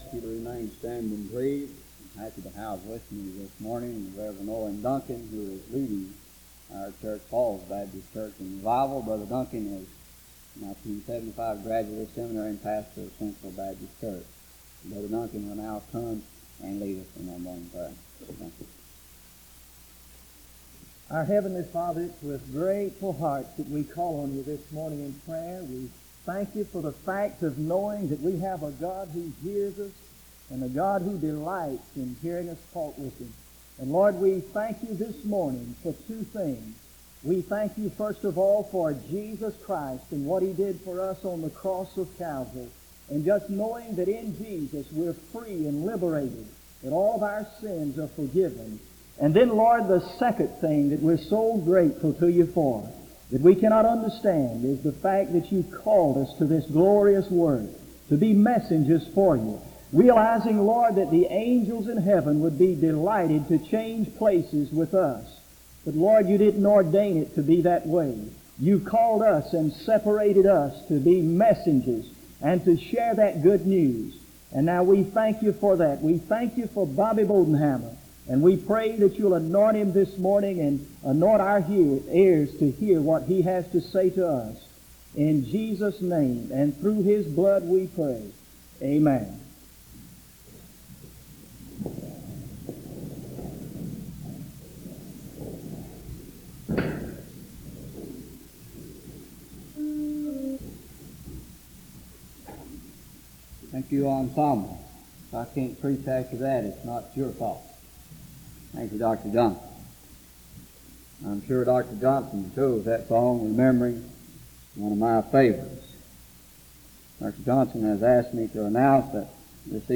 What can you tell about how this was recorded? The service begins with a prayer from 0:00-1:29. SEBTS Chapel and Special Event Recordings - 1970s